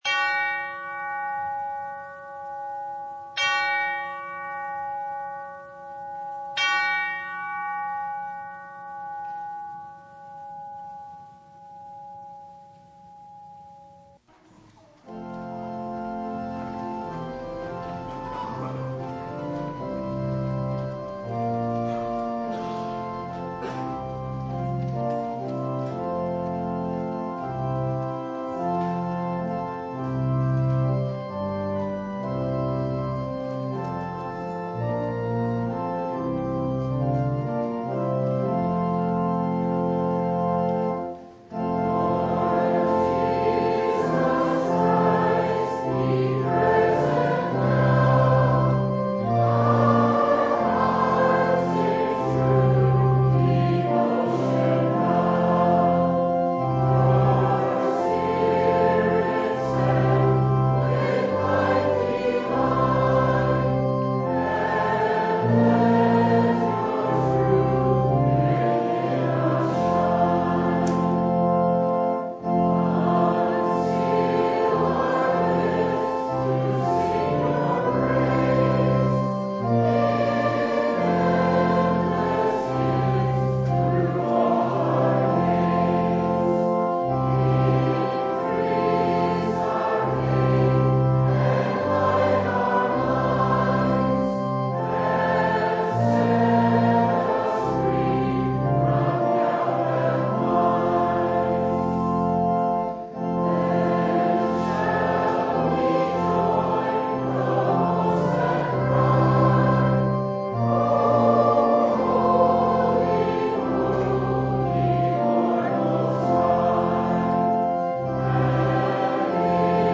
Luke 17:1-10 Service Type: Sunday Do we expect to be applauded for simply doing our duty?